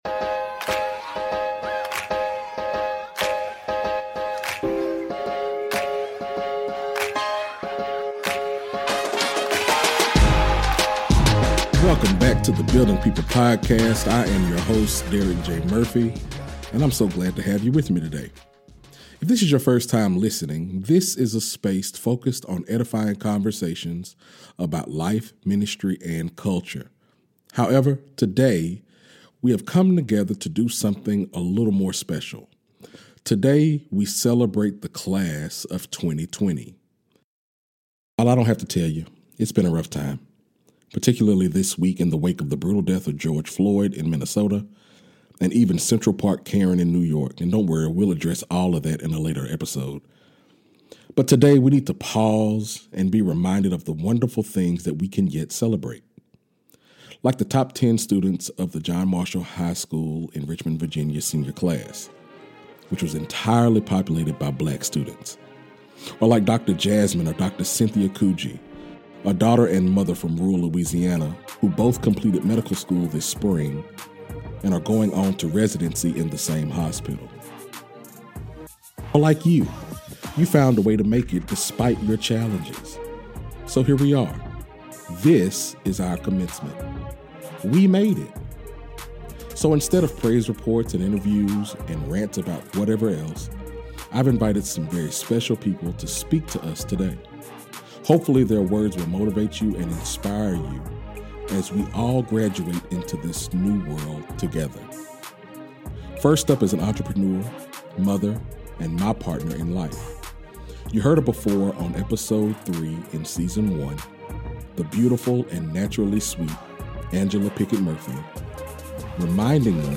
This episode is a celebration of "The Class of 2020", as we are all "graduating" into a new world. Check out these awesome speeches from these honored guests: